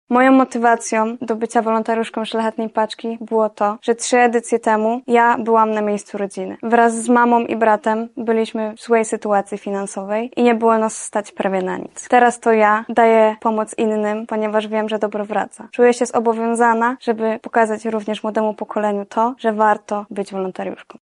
Wolontariuszka Szlachetnej Paczki.